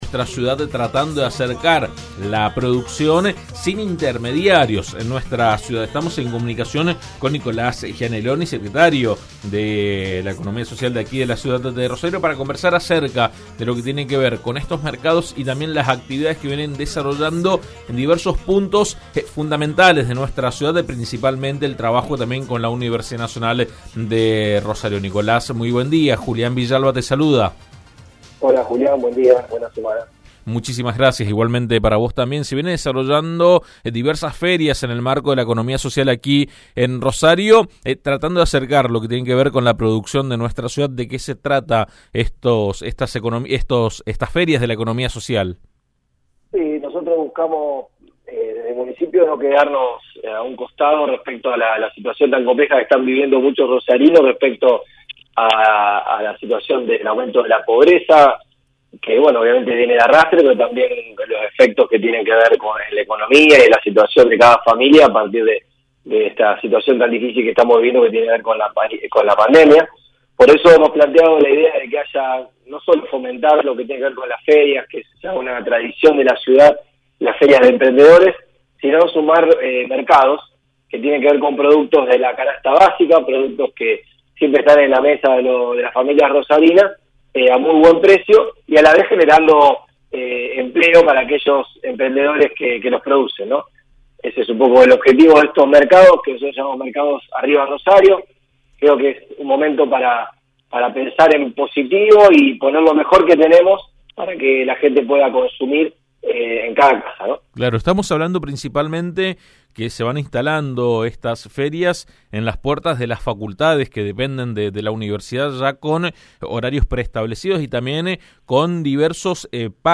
El Secretario de Desarrollo Humano y Hábitat de Rosario, Nicolás Gianelloni, habló con AM 1330 acerca del Mercado Social que rotará por los distintos barrios de Rosario con productos de la canasta básica a precios justos.